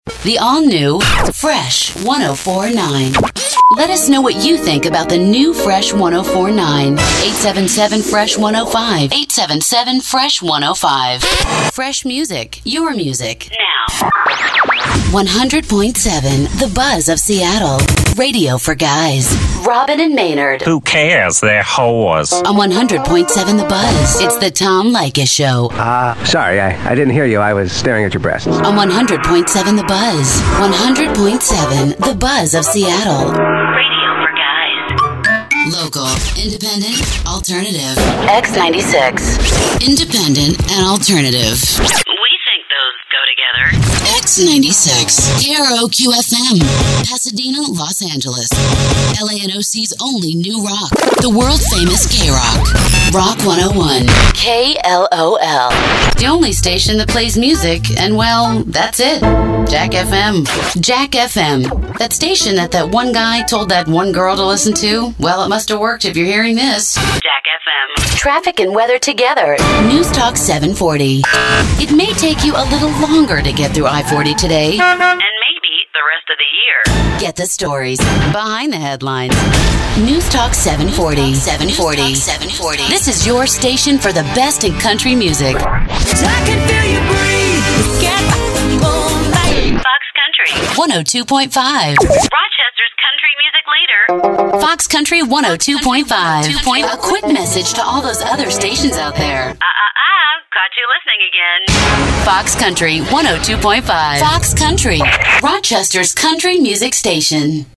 Radio Imaging Showreel
With her versatile, neutral US tones, Lori excels in both animation and commercial work.
Female
American Standard
Bright
Reassuring
Upbeat
Friendly
Playful
lori_alan_voiceover_radioimagingshowreel_gaming.mp3